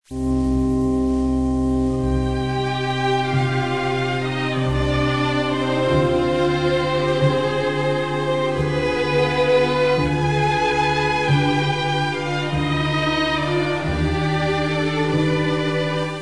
Adagio pour orgue & cordes